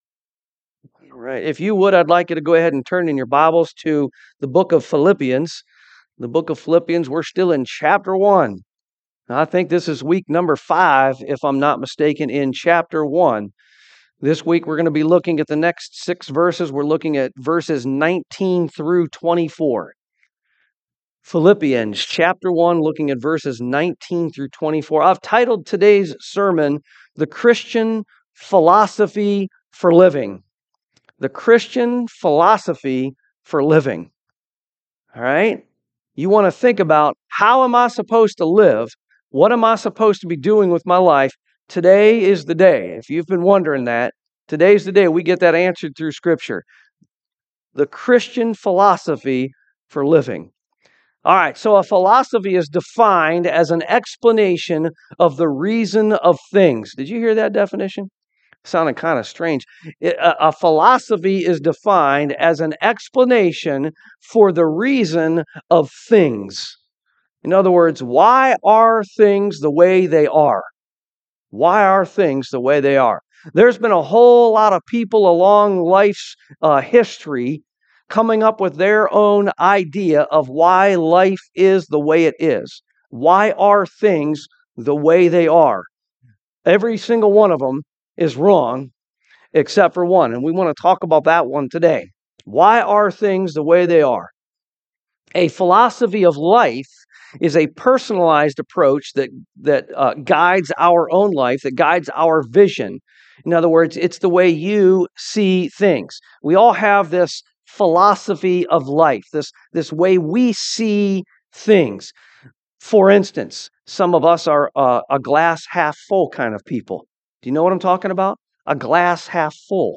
Philippians 1:19-24 Service Type: AM Christ is our life!